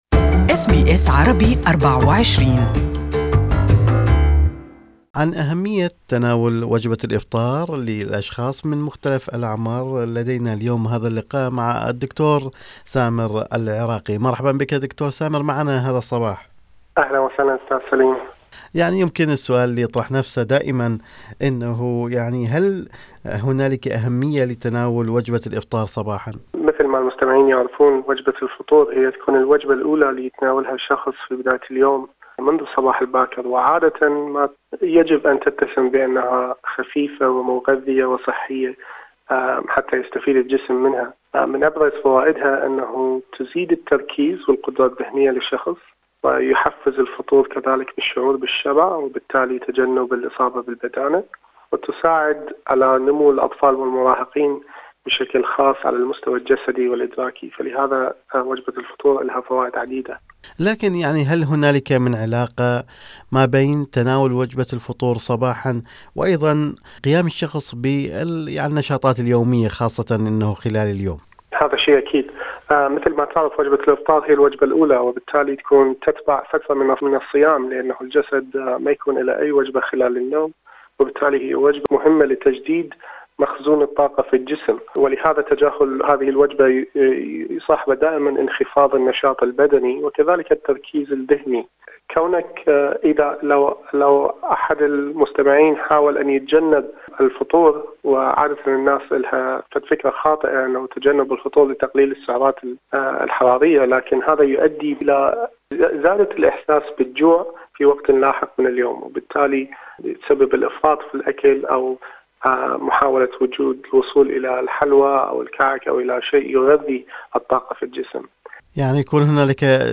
المزيد من التفاصيل في المقابلة التالية